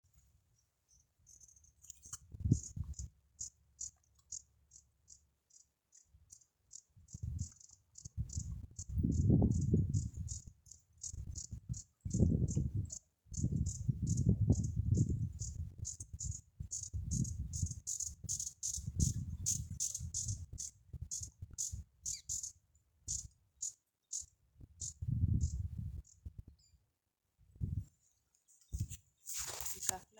Besourinho-de-bico-vermelho (Chlorostilbon lucidus)
Nome em Inglês: Glittering-bellied Emerald
Localidade ou área protegida: Cafayate
Condição: Selvagem
Certeza: Gravado Vocal